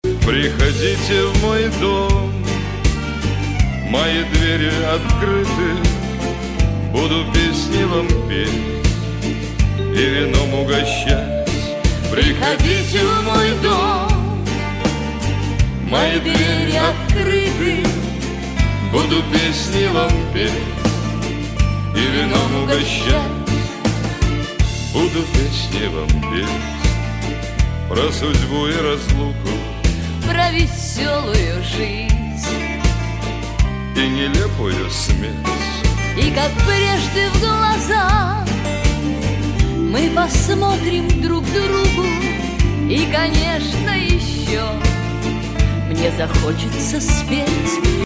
Русские рингтоны, Шансон рингтоны